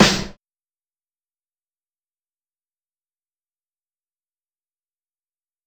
Snare (Whoa).wav